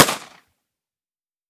38 SPL Revolver - Gunshot B 003.wav